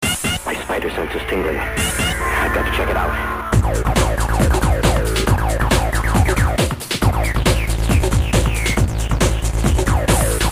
breakbeat track